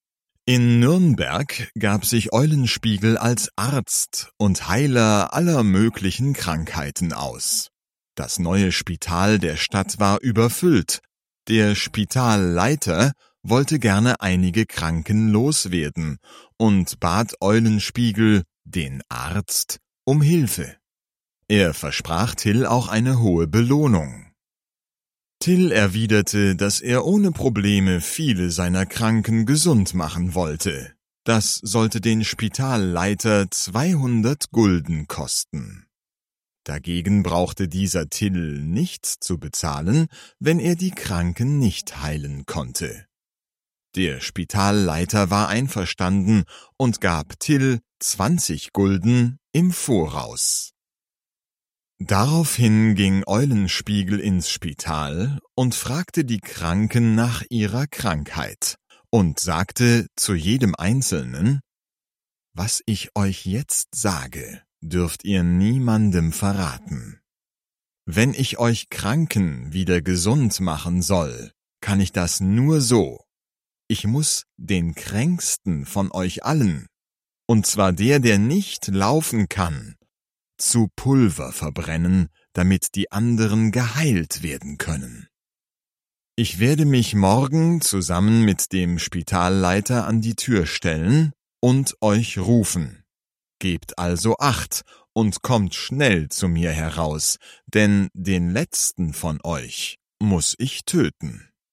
Till Eugenspiegel (DE) audiokniha
Ukázka z knihy